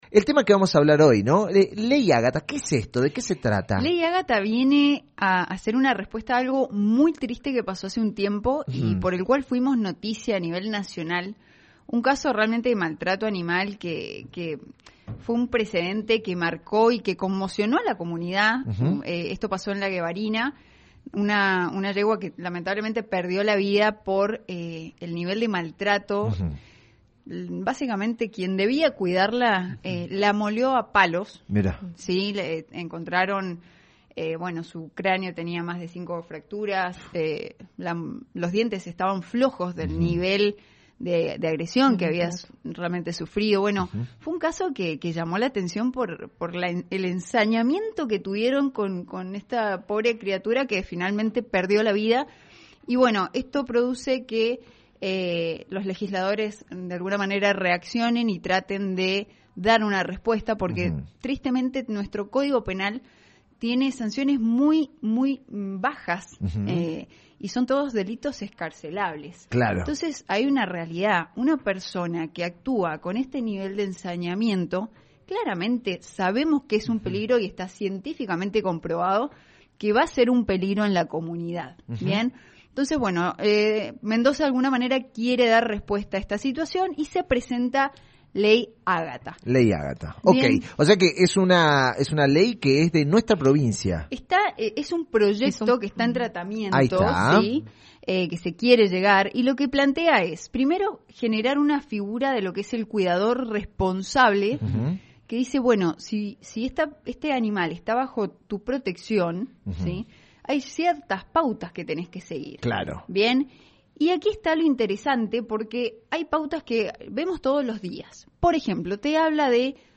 Durante la columna también participó telefónicamente